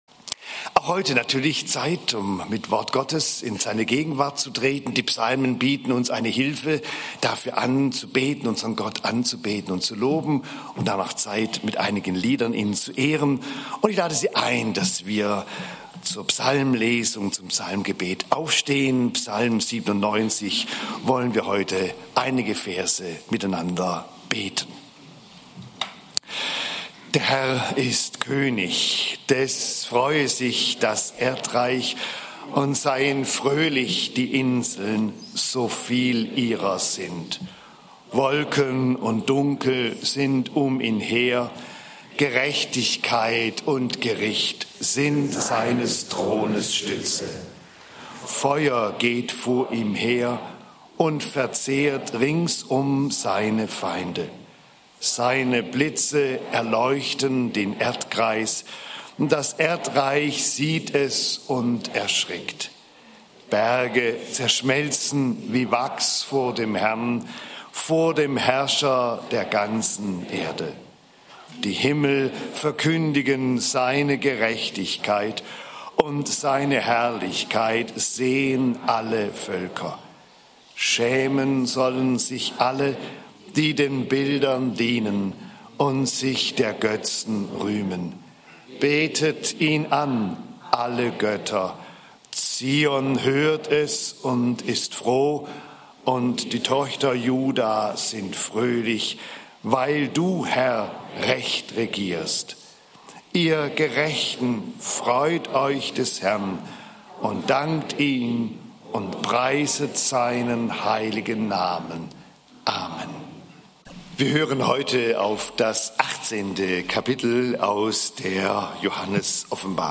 Die untrüglichen Zeichen seines Kommens (Offb. 18, 1-24) - Gottesdienst